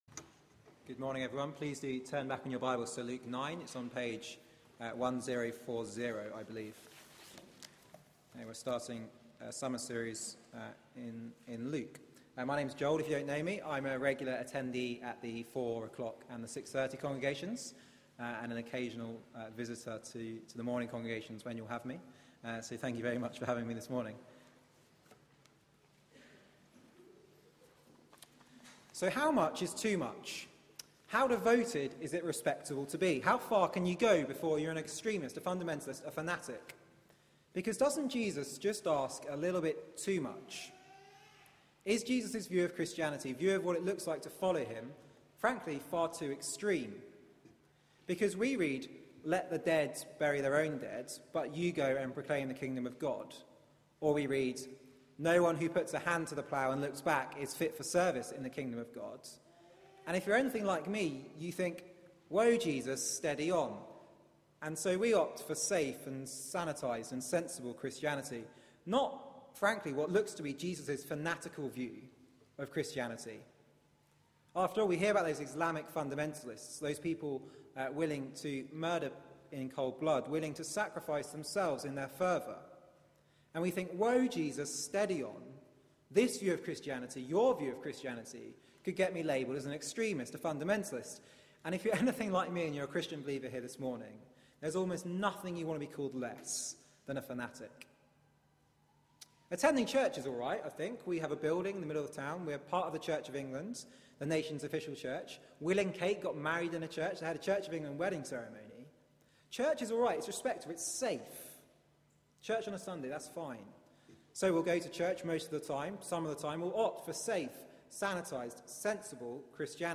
Media for 9:15am Service on Sun 21st Jul 2013 09:15 Speaker
The Road to Jerusalem 1 Sermon